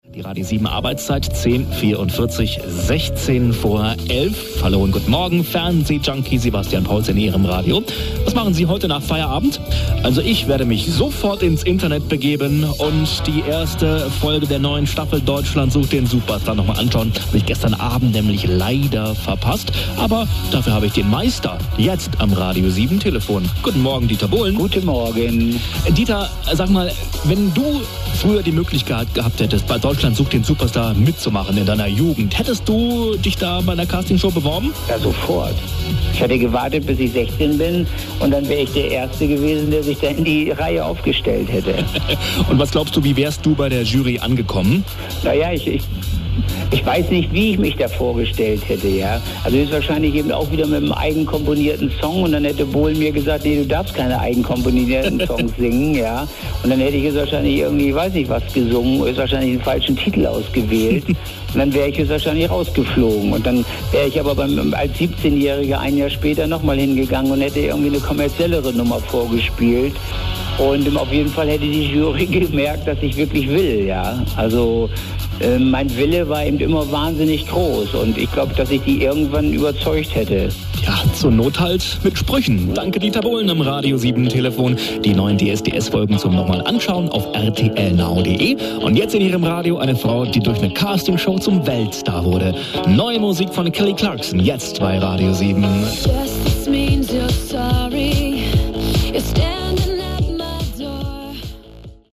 Berlin-Update und Bohlen-Interview